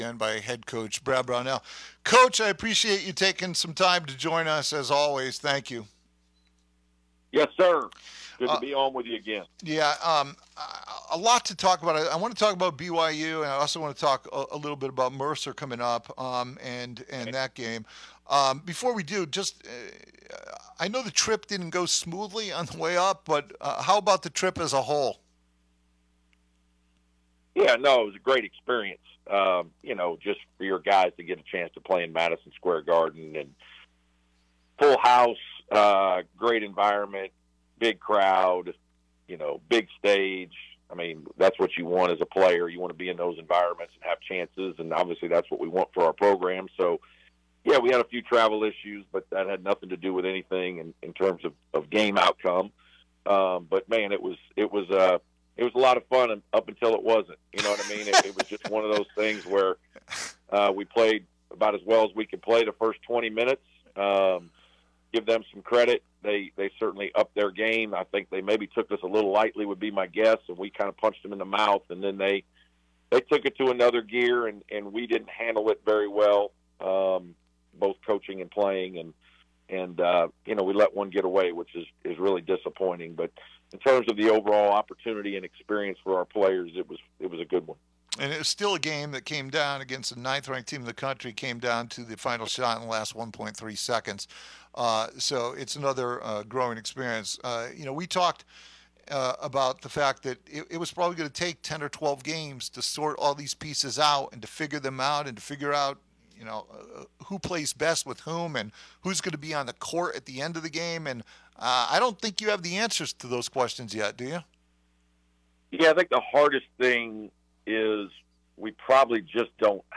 Special on-air Interviews